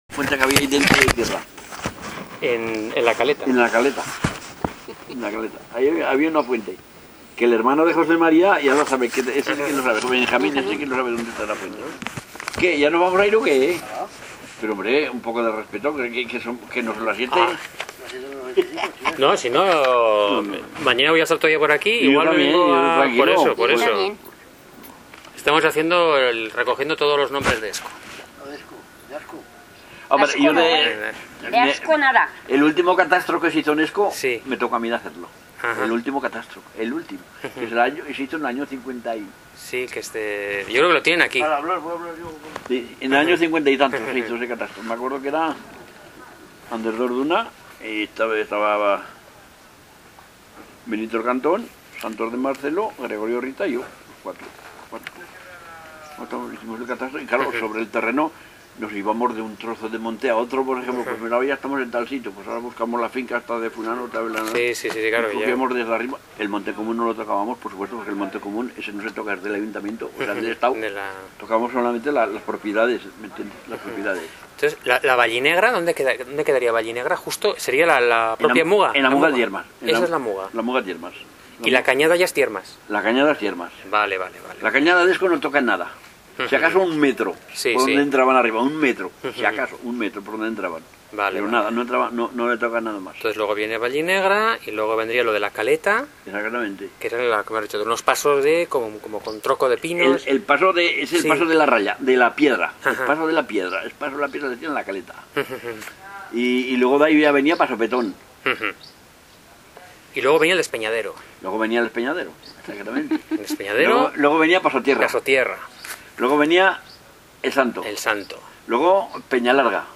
Grabaciones sobre nuestro pueblo emitidas en distintas radios y también grabaciones que hemos hecho en la asociación a nuestra gente sobre muy diversos temas relacionados con nuestro patrimonio cultural: vegetación, fauna, nombres de montes y campos...